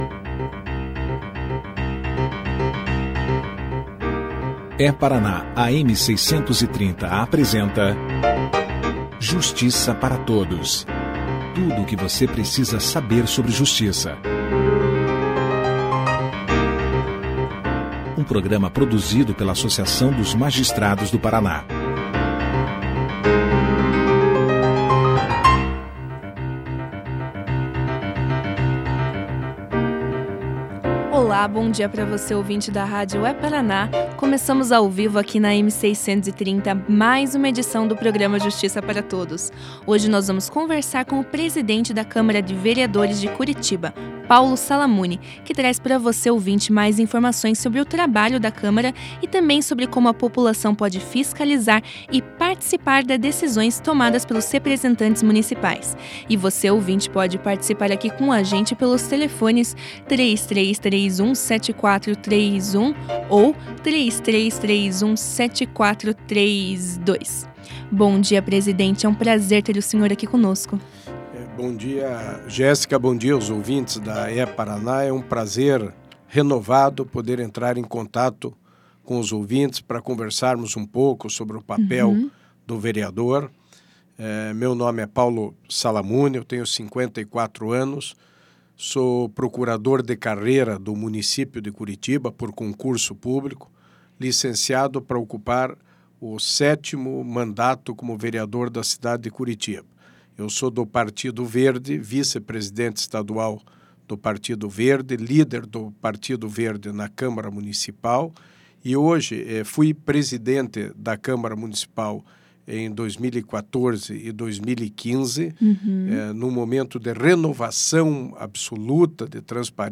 Ouça a entrevista do vereador Paulo Salamuni sobre o funcionamento do Legislativo Municipal na íntegra.